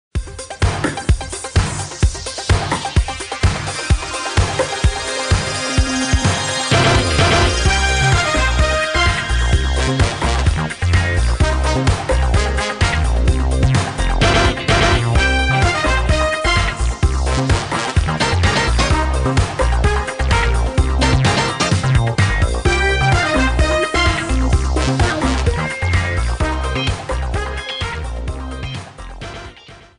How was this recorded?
Clipped to 30 seconds and applied fade-out Fair use music sample